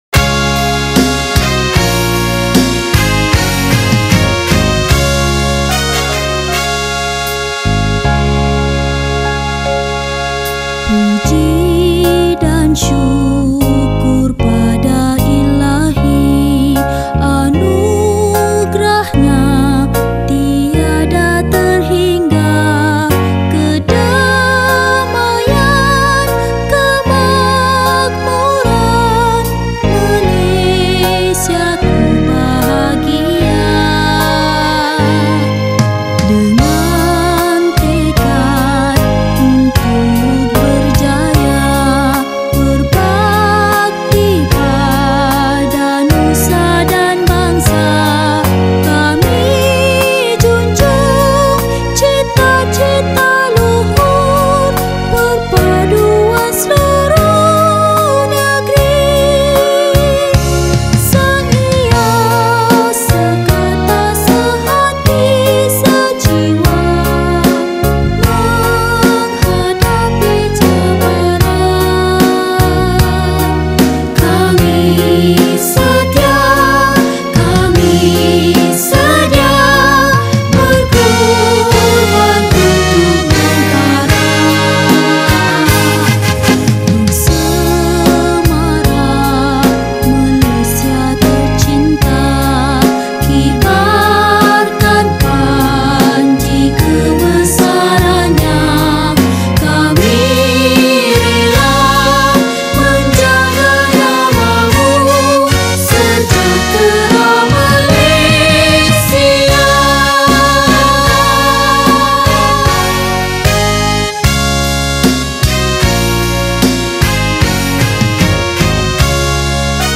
Patriotic Songs
Lagu Patriotik Malaysia